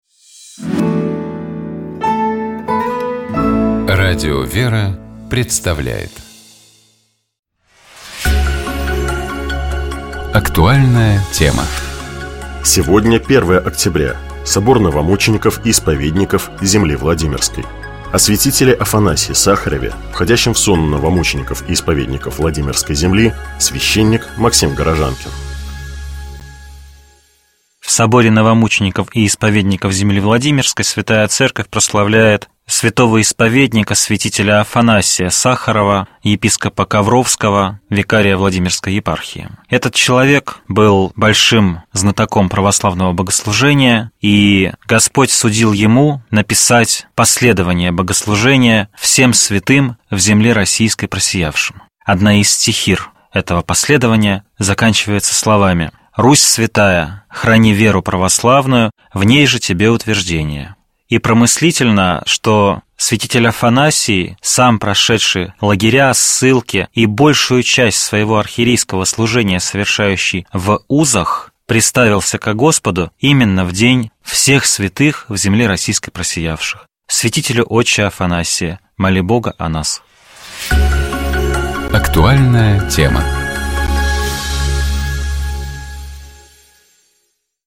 Кондак праздника поется на службе неоднократно.
Послушаем кондак Нерукотворному образу Господню в исполнении хора Сретенского монастыря г. Москвы.